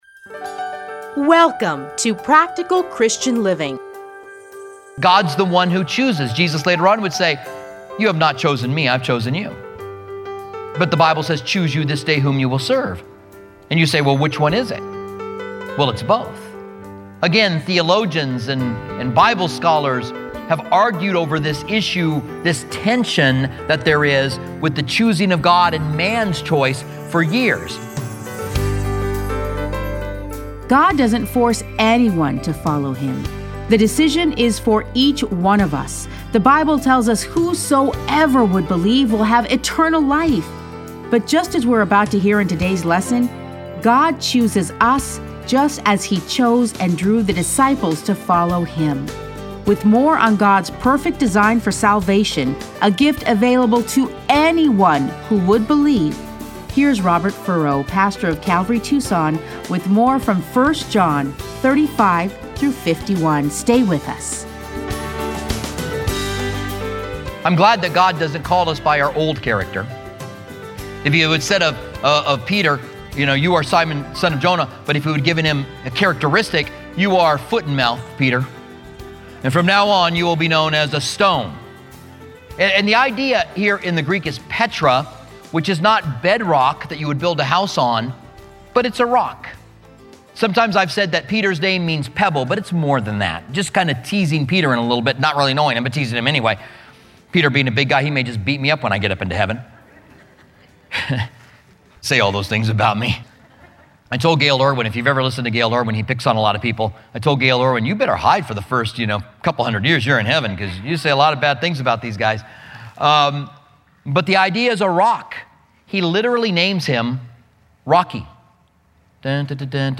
Listen to a teaching from John 1:35-51.